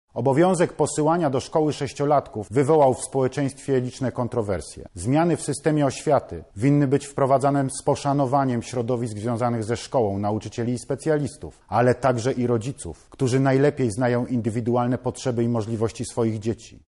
Ogłosił to podczas wieczornego orędzia do obywateli.